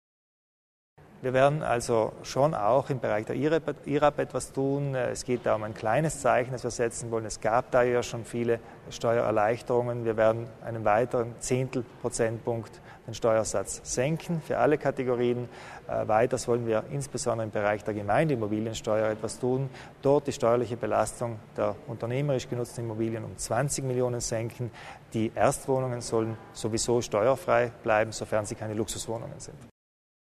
Landeshauptmann Kompatscher erläutert die Pläne zur Steuerentlastung